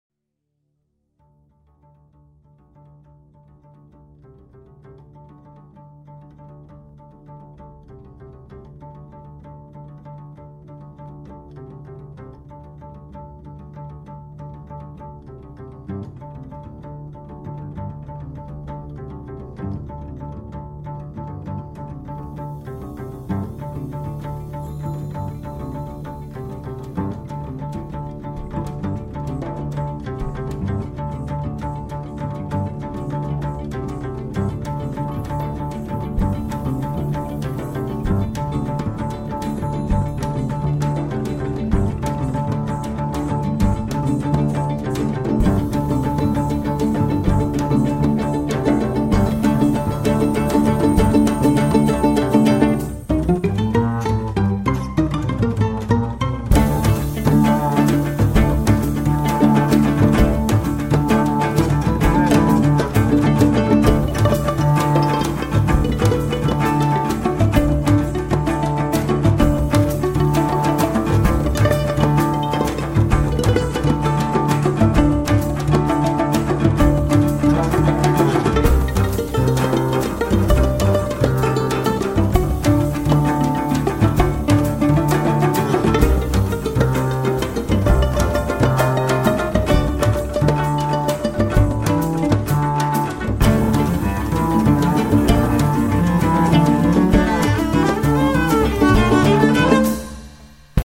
dai continui cambi di umori